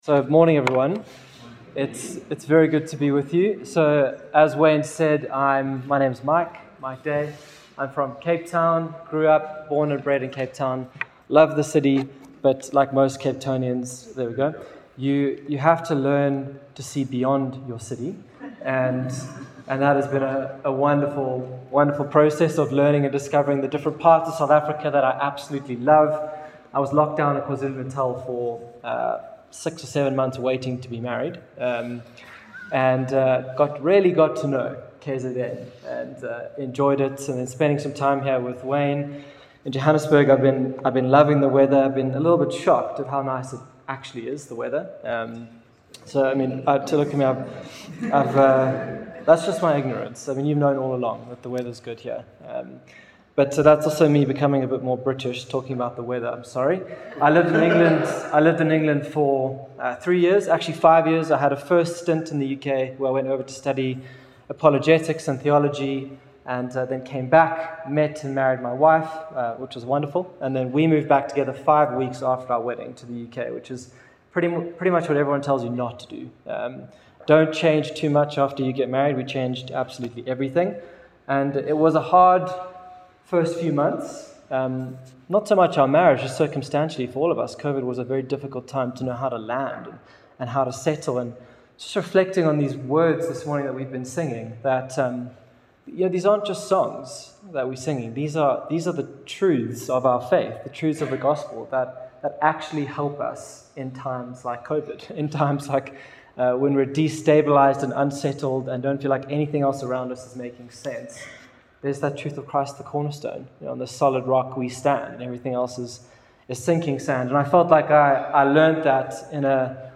From Hillside Vineyard Christian Fellowship, at Aan-Die-Berg Gemeente. Johannesburg, South Africa.